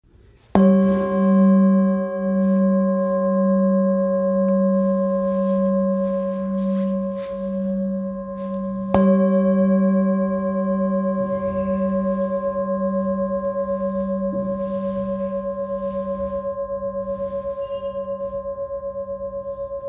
Singing Bowl, Buddhist Hand Beaten, with Fine Etching Carving, Shakyamuni Buddha
Material Seven Bronze Metal